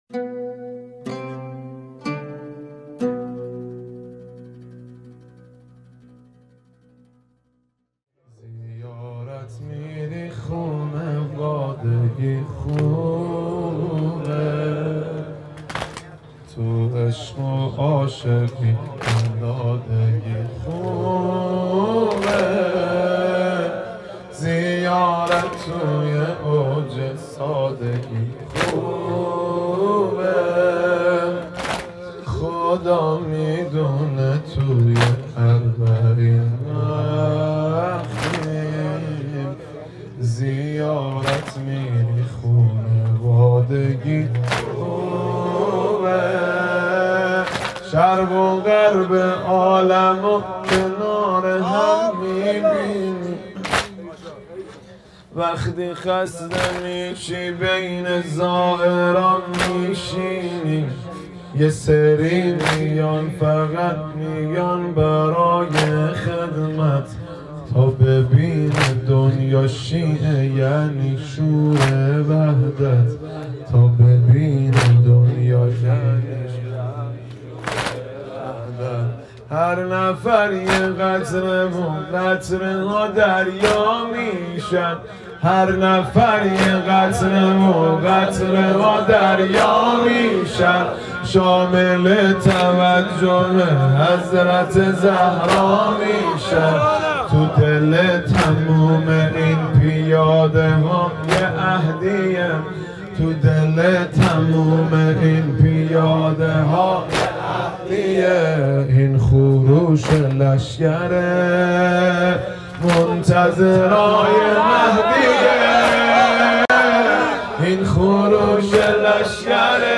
سنگین | وقتی خسته میشیم بین زائرات میشینیم
مداحی حاج مهدی رسولی | اجتماع زائران آذری‌زبان اربعین | 15 آبان 1396 - 6 نوامبر 2017 | مسیر نجف به کربلا، عمود 836 موکب فطرس